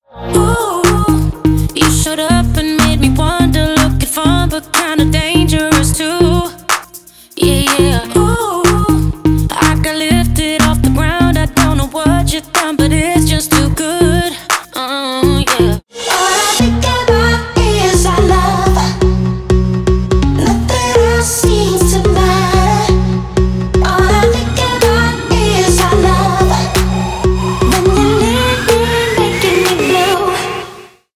Вроде какой-то органный звук, синус и в квинту играет.